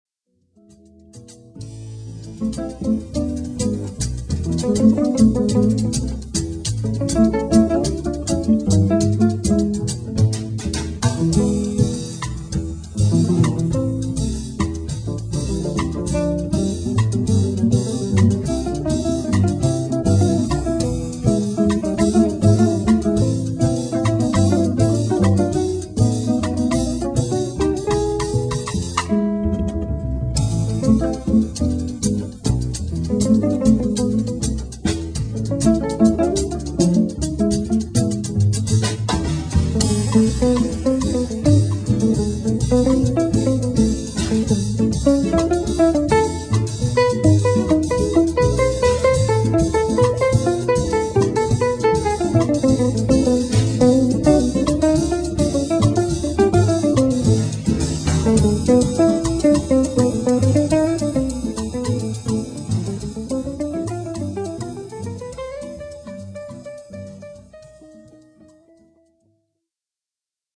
Please note: These samples are not of CD quality.
Jazz and Classical Guitarist